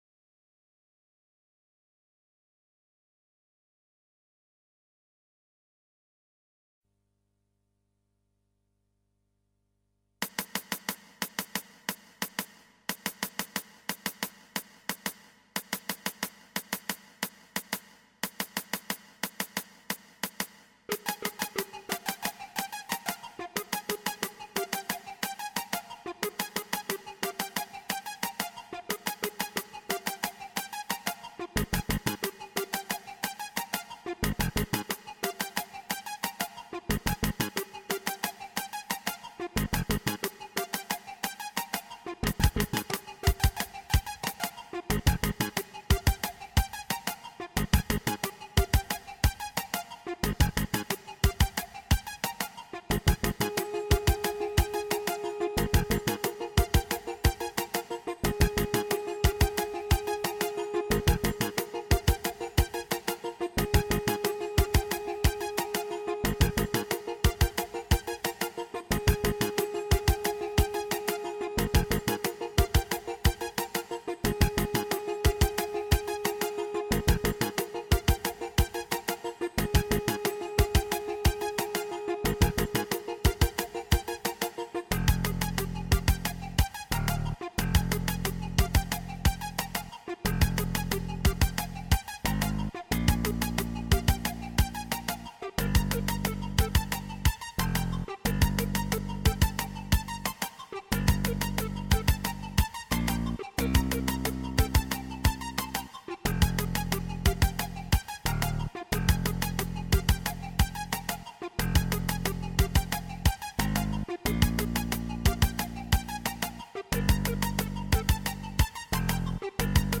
Digital Sequencer and Sound Module
read by GS standard
Synthesis: PCM rompler
Effects: reverb / chorus